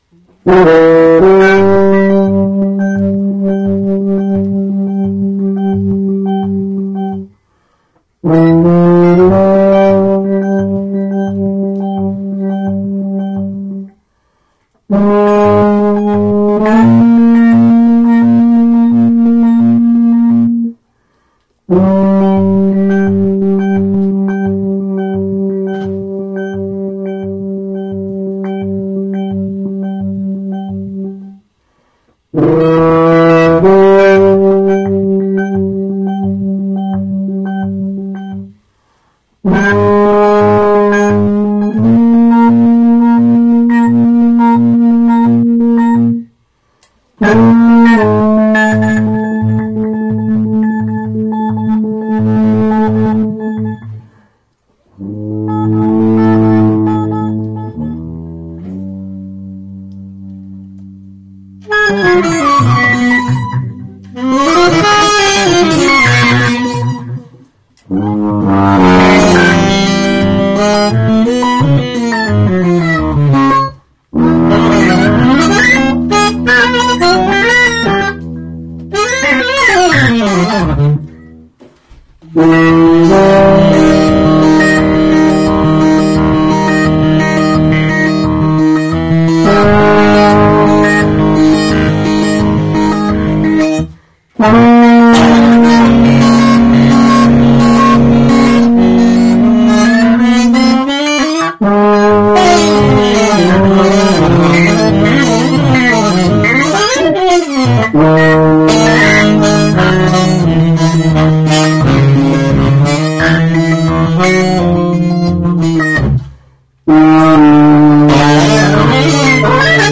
Recorded live at Downtown Music Gallery in Manhattan
tuba
alto saxophone, electronics
Stereo (Zoom)